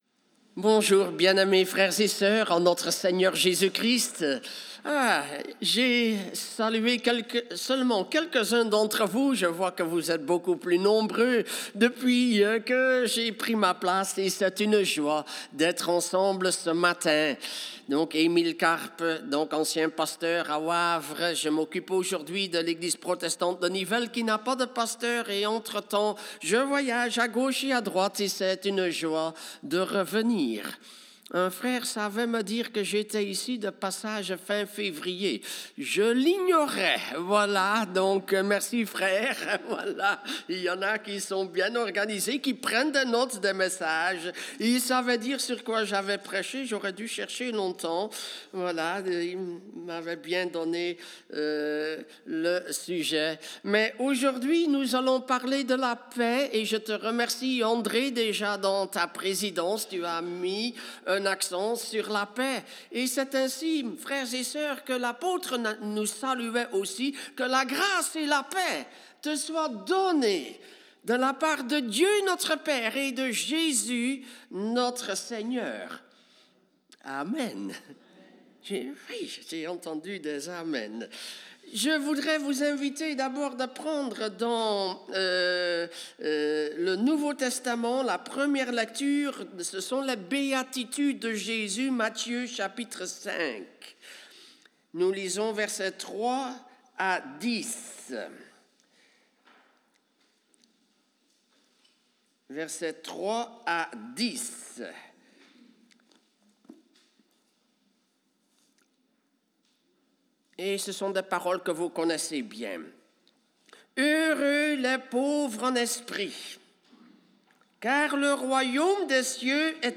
Ecouter le message